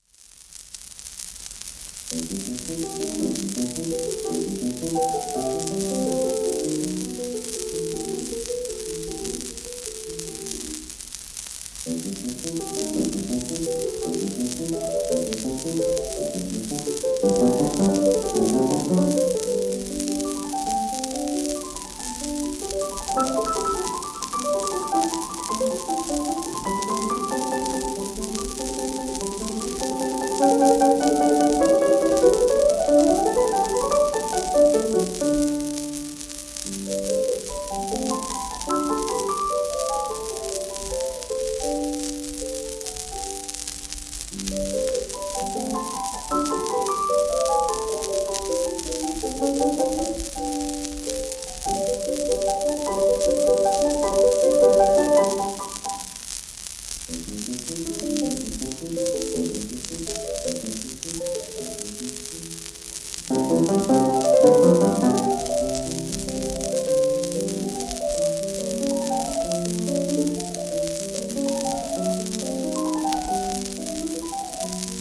モニク・ド・ラ・ブルショルリ(P:1915-1972)
フランスのピアニスト。
シェルマン アートワークスのSPレコード